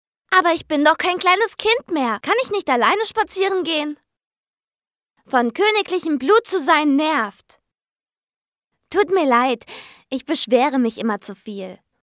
deutsche Profisprecherin. Als Sprecherin von Mädchen bis junge Frau einsetzbar
Sprechprobe: Sonstiges (Muttersprache):
german female voice over talent.